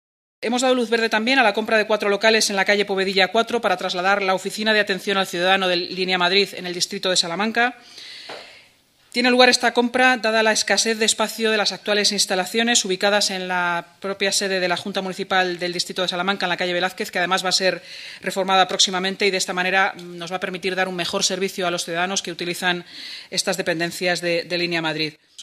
Nueva ventana:La portavoz municipal, Inmaculada Sanz